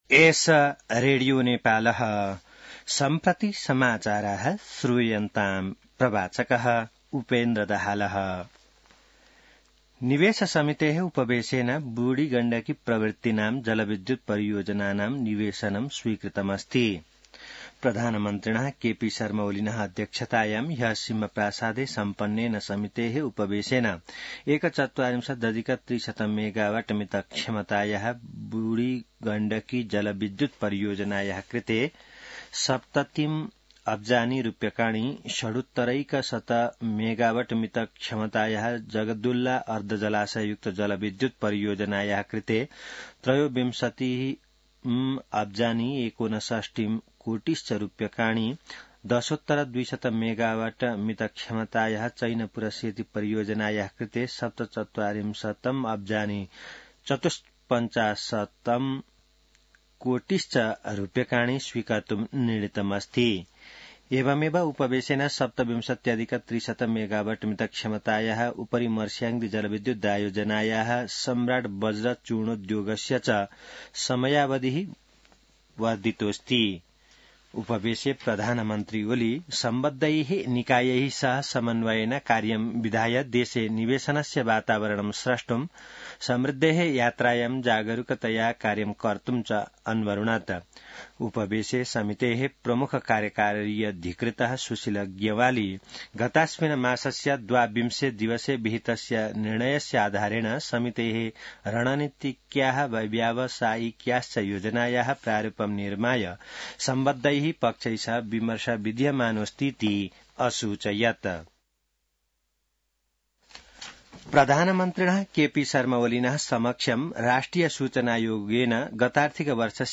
संस्कृत समाचार : १५ मंसिर , २०८१